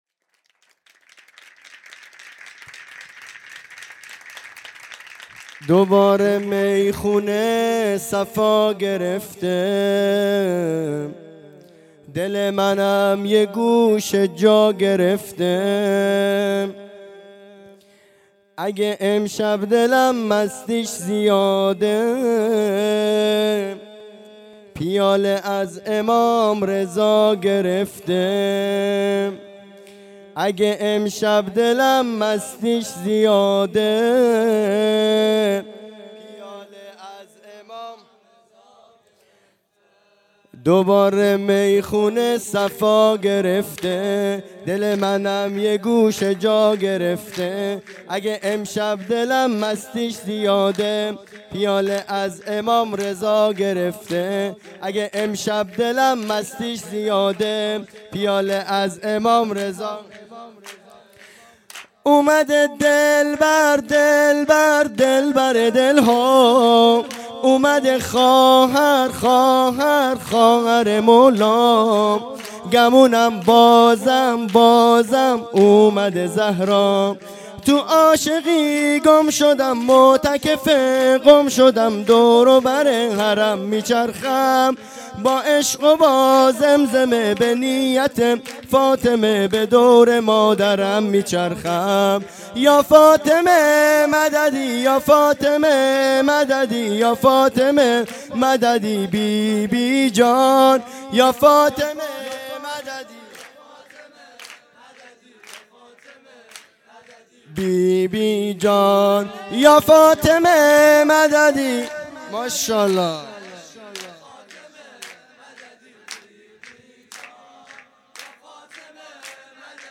خیمه گاه - هیئت بچه های فاطمه (س) - سرود | دوباره میخونه صفا گرفته
جلسۀ هفتگی (به مناسبت ولادت حضرت معصومه(س))